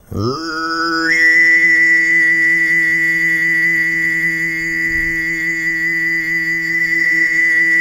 TUV1 DRONE08.wav